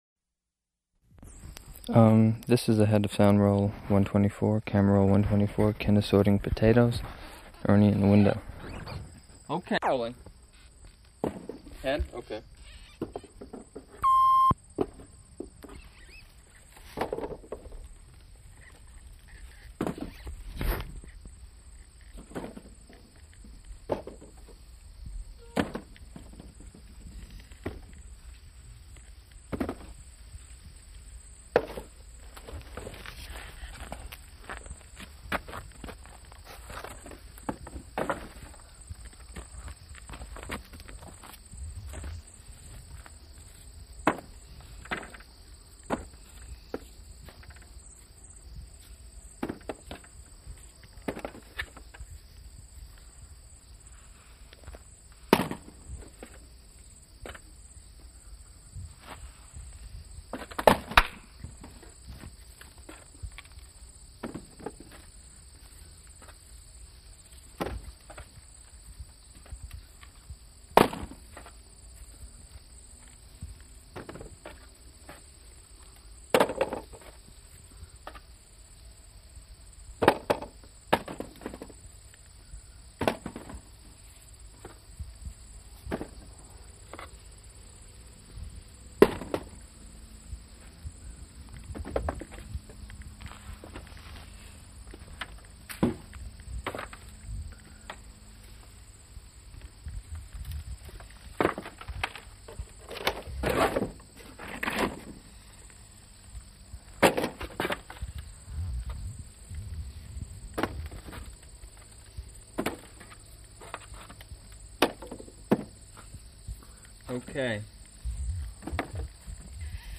(Interviewer)
Format 1 sound tape reel (Scotch 3M 208 polyester) : analog ; 7 1/2 ips, full track, mono.
Chelsea (inhabited place) Vermont (state)